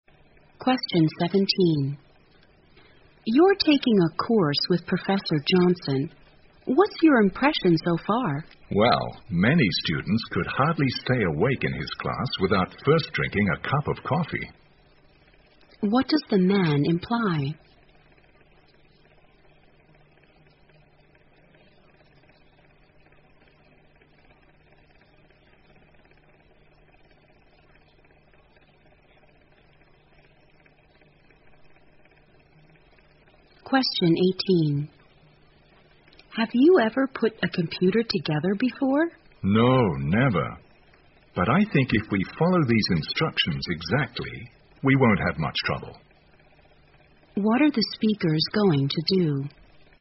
在线英语听力室035的听力文件下载,英语四级听力-短对话-在线英语听力室